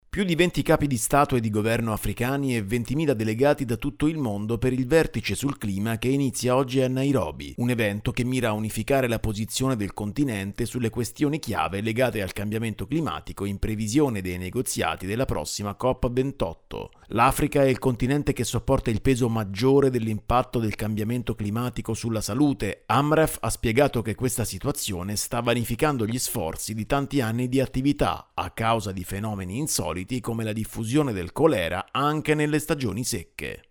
Si apre oggi in Kenya il Vertice africano sul clima, in vista della COP28 del prossimo novembre. Il servizio